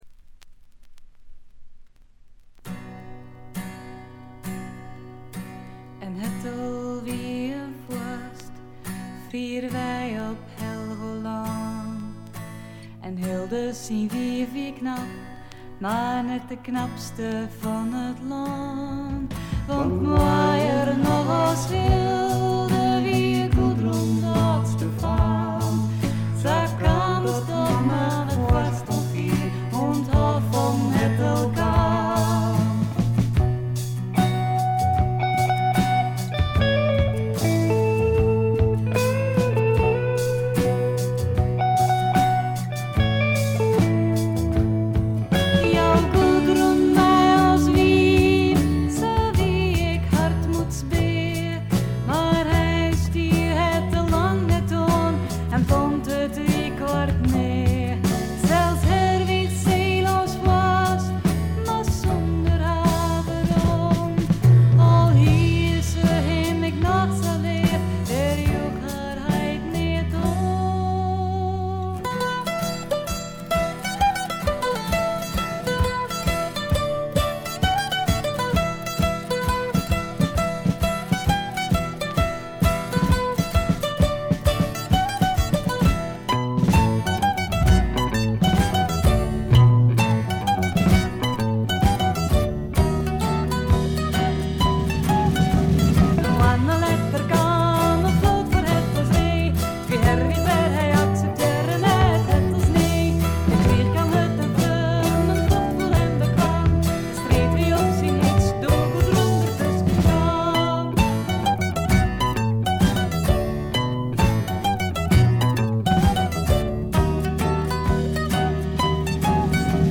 異国情緒が漂う女性ヴォーカルの美声に思わずくらくらしてしまうフィメールフォークの名作でもあります。
アコースティック楽器主体ながら多くの曲でドラムスも入り素晴らしいプログレッシヴ・フォークを展開しています。
試聴曲は現品からの取り込み音源です。